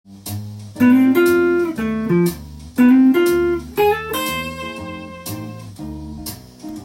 譜面通り弾いてみました
有名ギタリスト達が使うフレーズです。